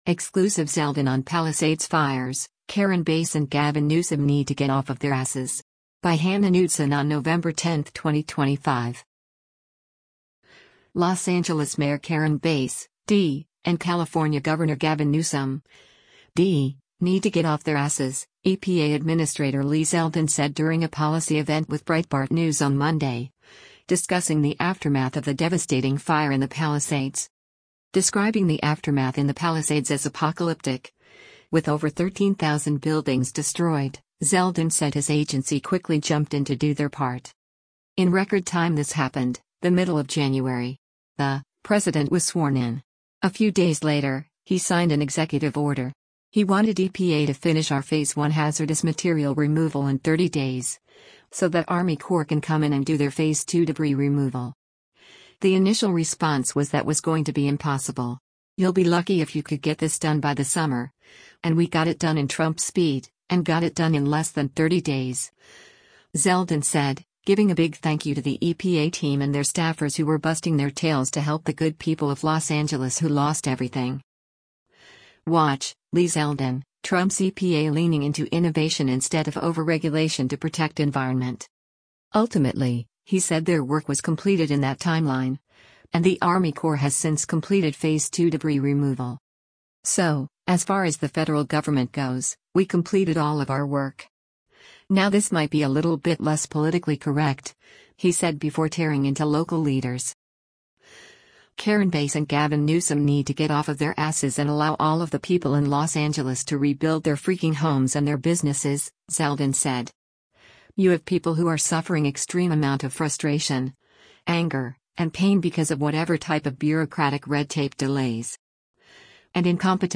Los Angeles Mayor Karen Bass (D) and California Gov. Gavin Newsom (D) need to “get off their asses,” EPA Administrator Lee Zeldin said during a policy event with Breitbart News on Monday, discussing the aftermath of the devastating fire in the Palisades.